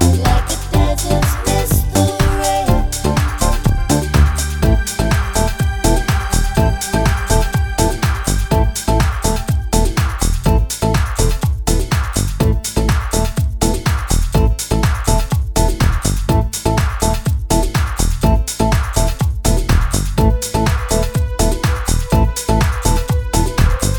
No Backing Vocals Pop (1980s)